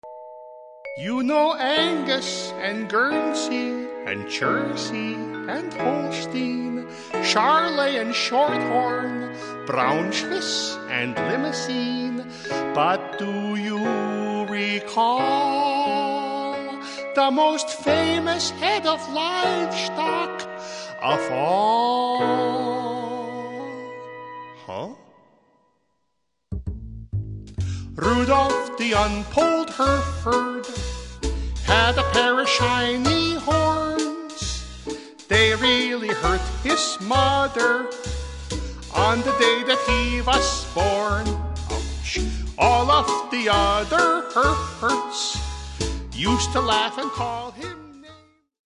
well-recorded and hilarious.